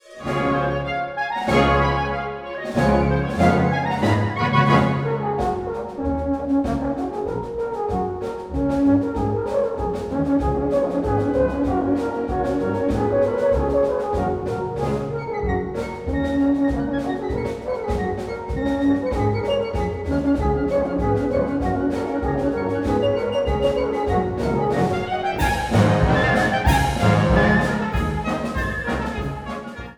☰ Schweizer Volksmusik
für Alphorn und Blasorchester
Beschreibung:Blasmusik; Volksmusik; Alphornmusik
Besetzung:Blasorchester (Harmonie), Alphorn